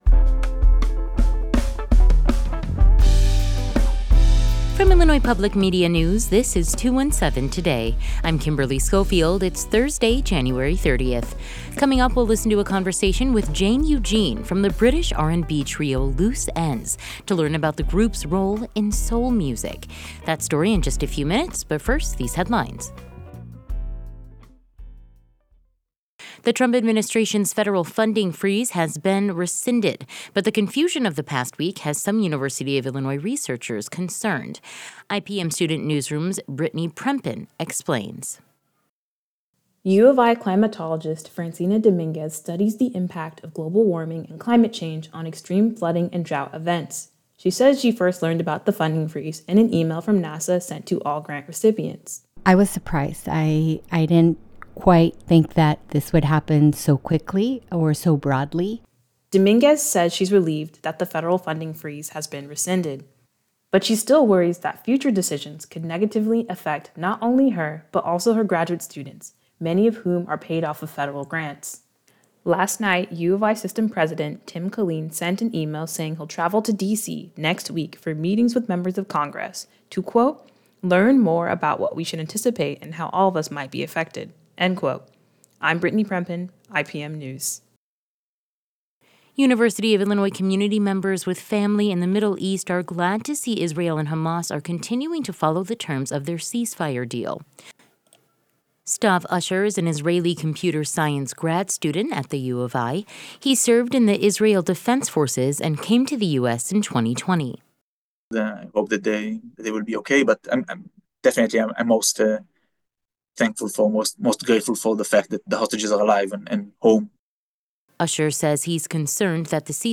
Today's headlines: